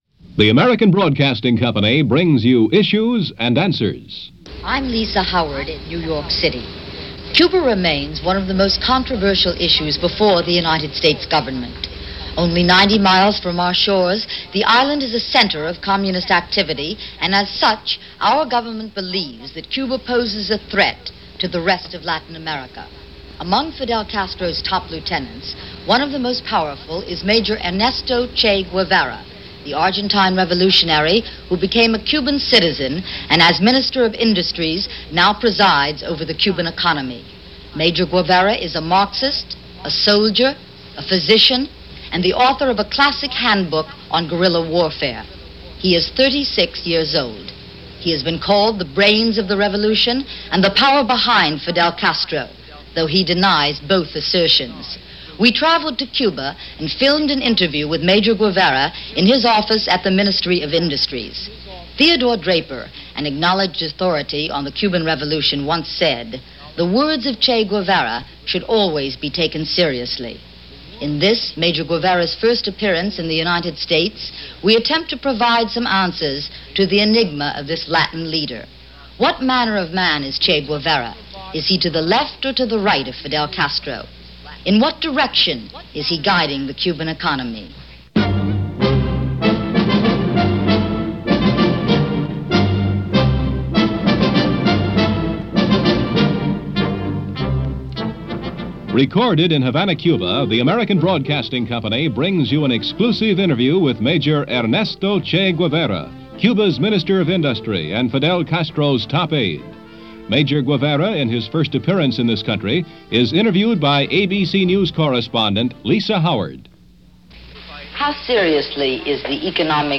He has come to symbolize the mythic-tragic Revolutionary figure of the 1960’s. But there are very few recordings of his voice. And this interview, done as part of the ABC News series Issues and Answers first broadcast on March 24, 1964, may very well be the only such interview Guevara did with mainstream U.S. Media.